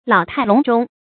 注音：ㄌㄠˇ ㄊㄞˋ ㄌㄨㄙˊ ㄓㄨㄙ
老態龍鐘的讀法